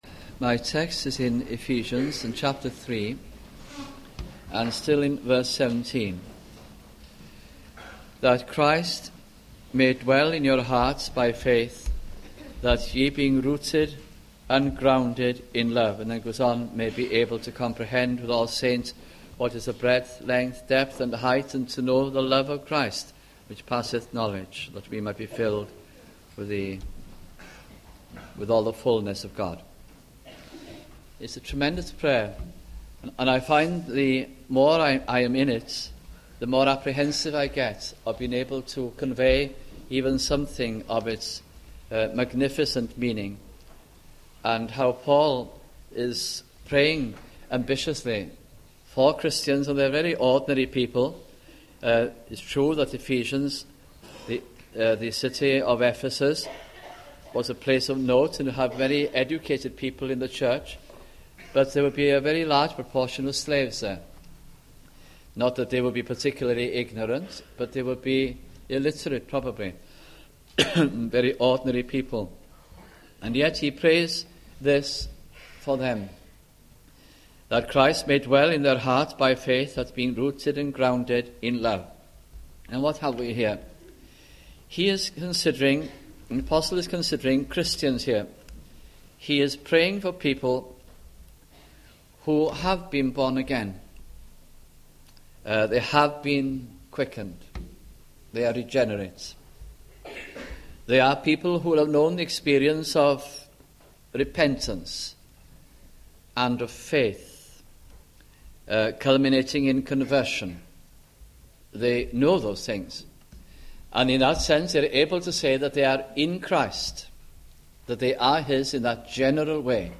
» Ephesians Series 1991 » sunday morning messages